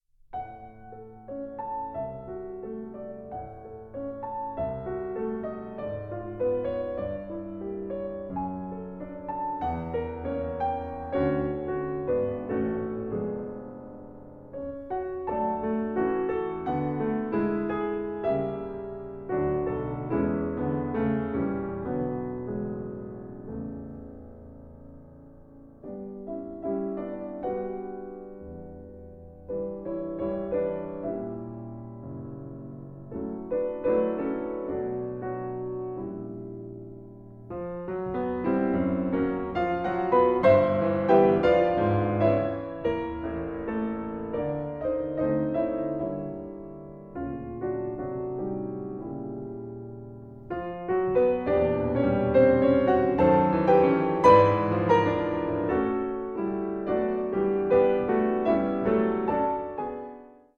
Stereo
Andante sostenuto